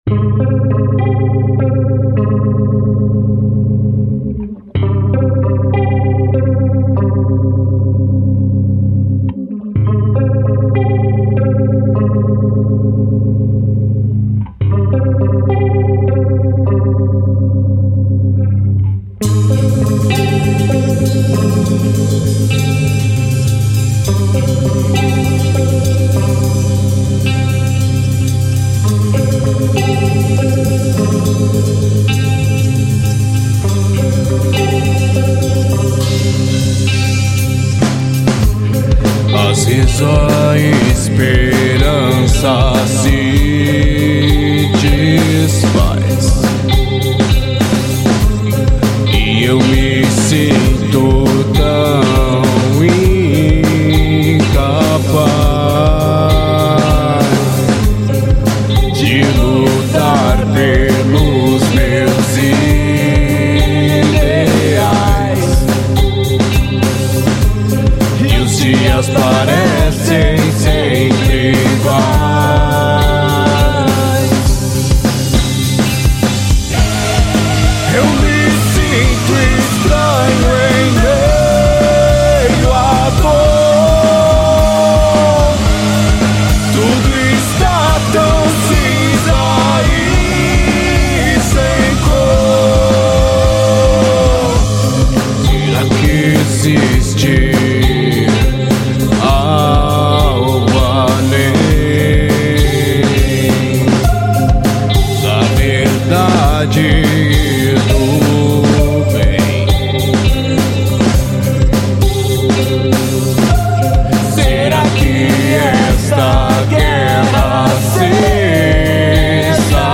EstiloNew Metal